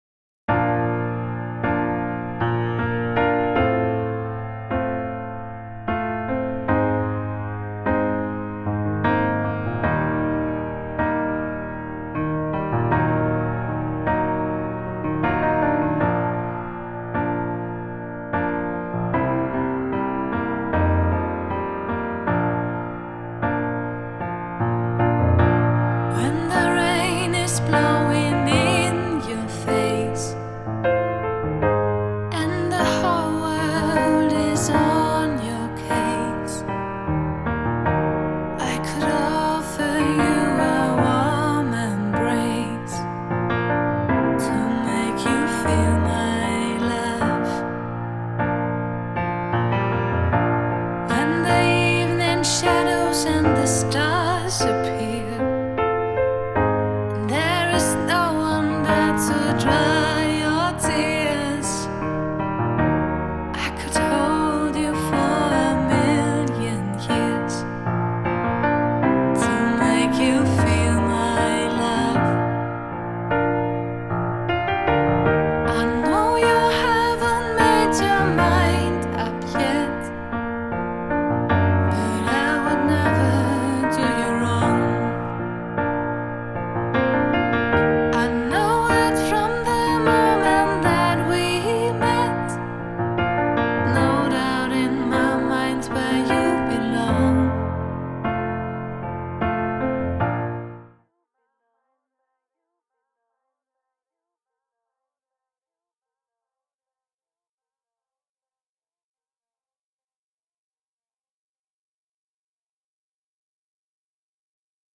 Gefühlvolle Balladen und mehr